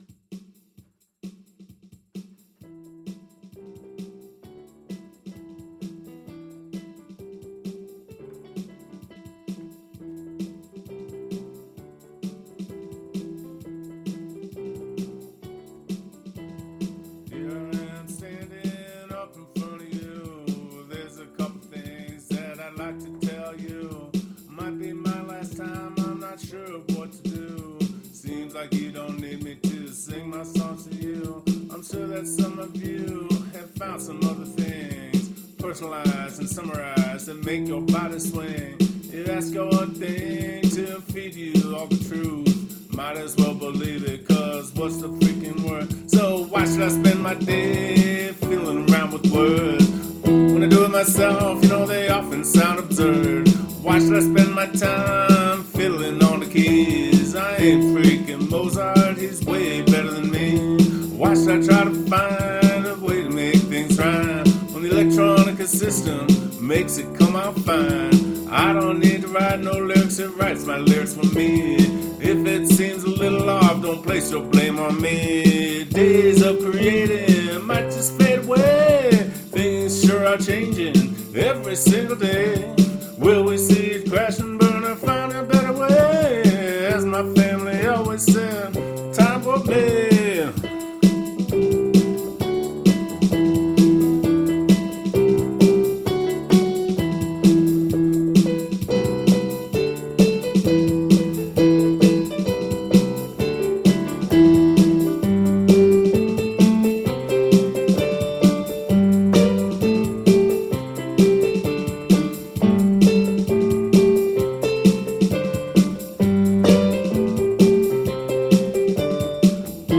Live recording
Key: Em BPM: 120